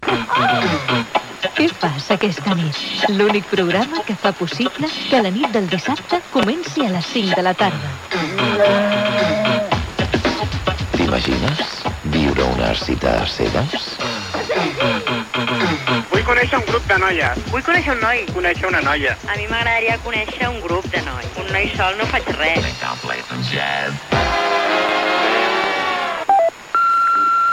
Promoció del programa
FM
Gravació realitzada a València.